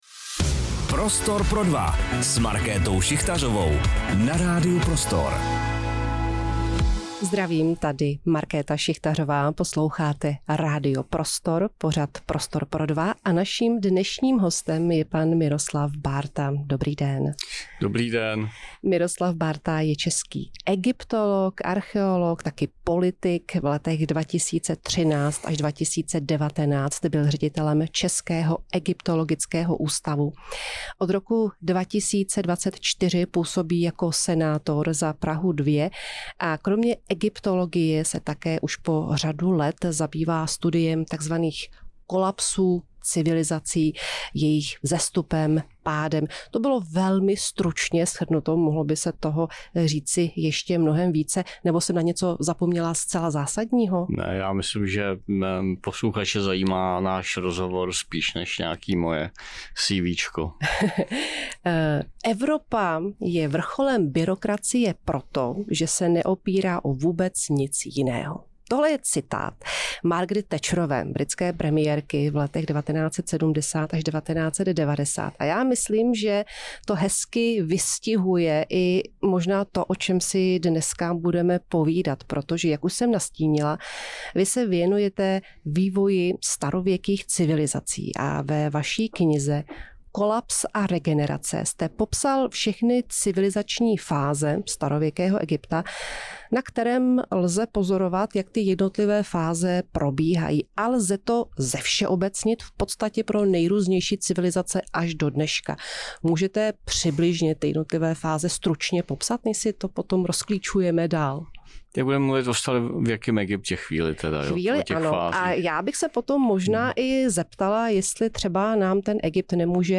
Rozhovor s Miroslavem Bártou | Radio Prostor
Do Prostoru pro dva s Markétou Šichtařovou dorazil egyptolog, archeolog a politik Miroslav Bárta.